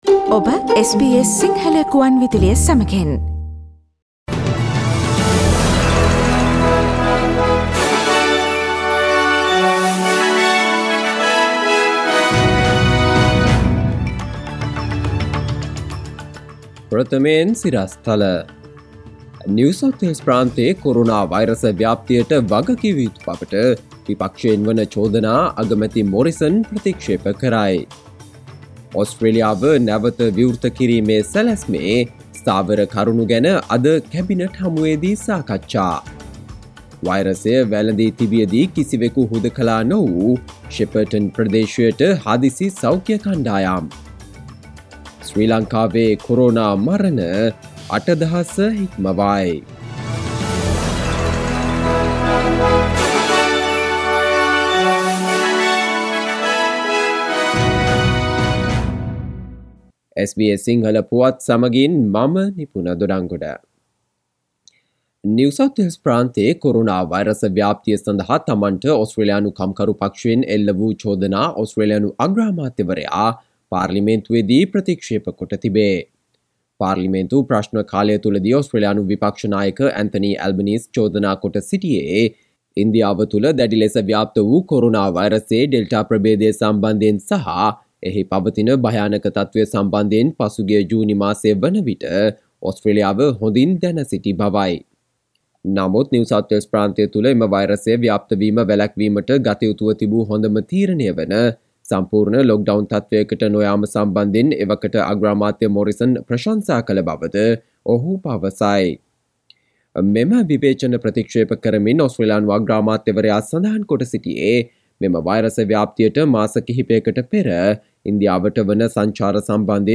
සවන්දෙන්න 2021 අගෝස්තු 27 වන සිකුරාදා SBS සිංහල ගුවන්විදුලියේ ප්‍රවෘත්ති ප්‍රකාශයට...